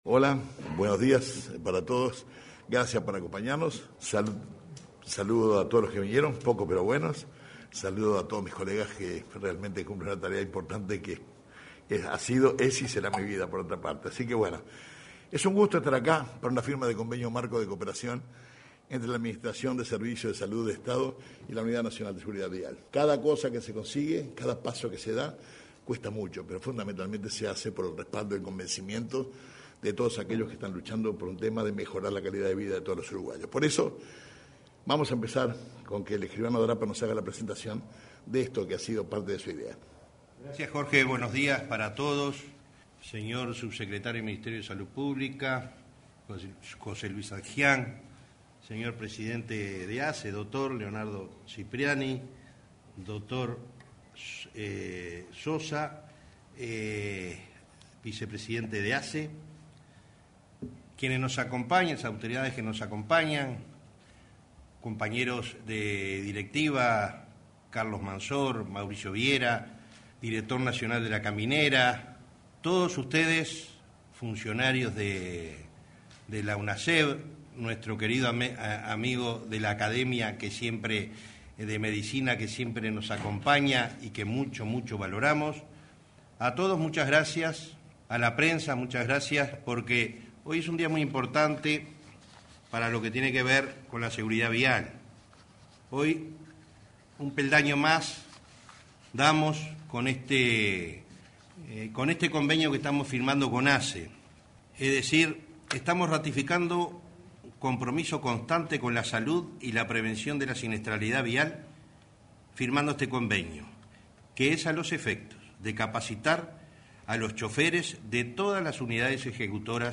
Oratoria del subsecretario de MSP, José Luis Satdjian, del titular de ASSE, Leonardo Cipriani, y del presidente de Unasev, Alejandro Draper
El subsecretario de Salud Pública, José Luis Satdjian, juanto a los titulares de ambos organismos, Leonardo Cipriani y Alejandro Draper, ahondaron sobre los términos del acuerdo.